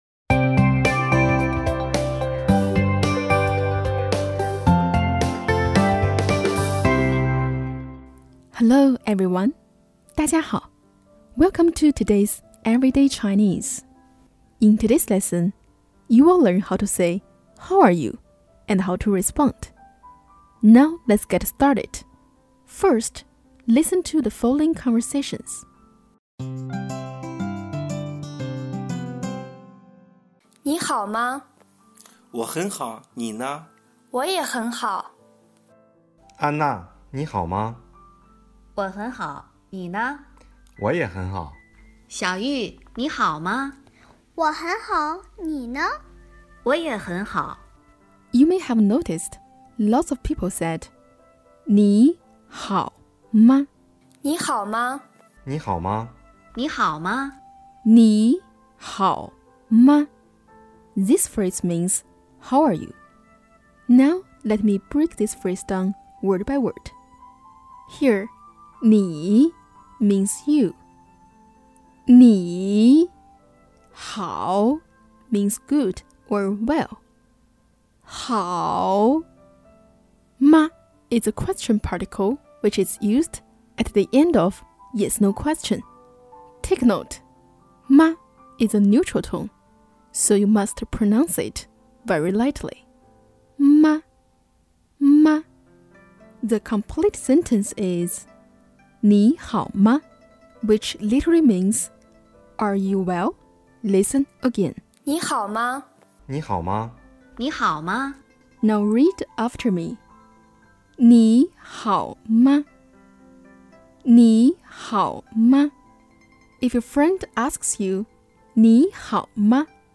你好, Welcome to Everyday Chinese. In today’s lesson, we are going to learn how to say “how are you” and how to answer it in Chinese.